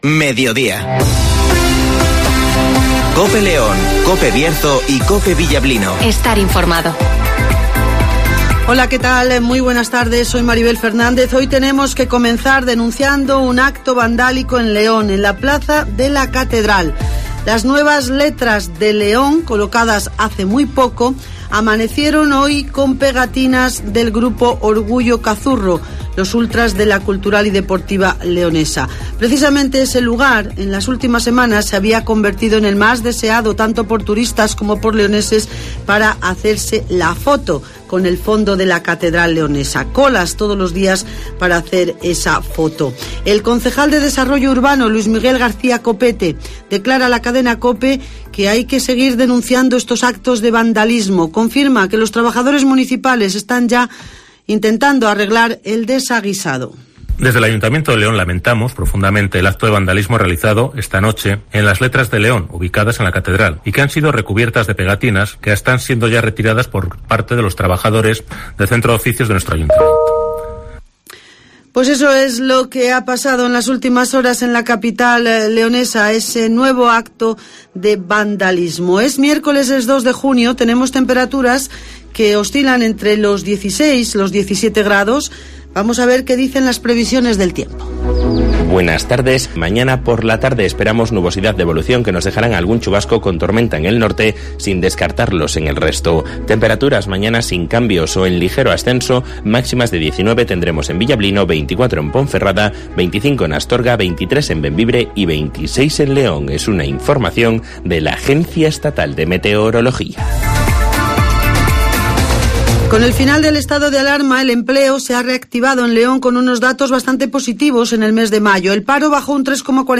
Repaso a la actualidad informativa de la provincia de León. Escucha aquí las noticias con las voces de los protagonistas.